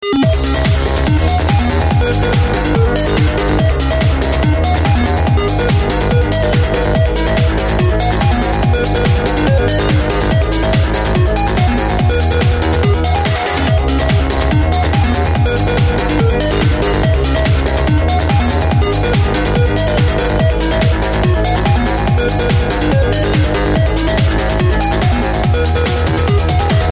(Мелодия для мобильного)